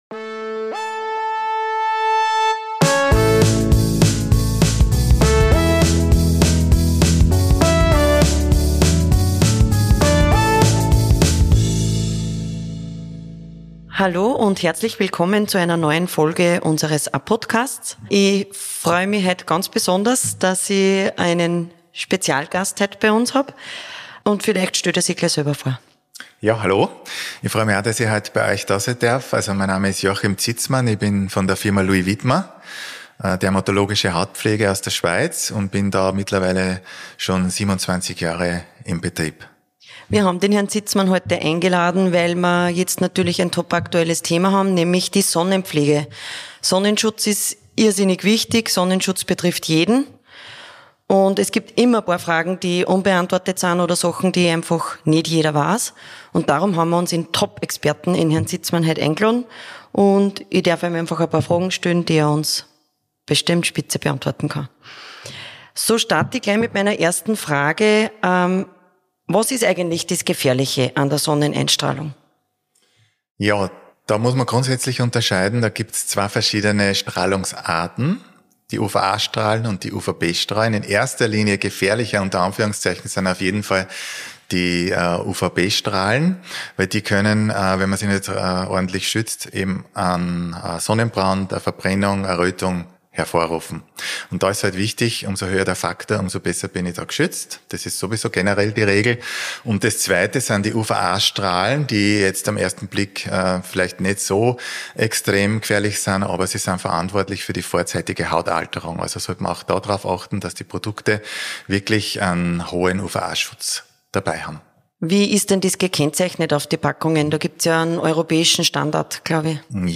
#6 Sonnenschutz für alle: Mythen, Fakten, Tipps für Groß & Klein ~ Apodcast: Der Gesundheits-Talk Podcast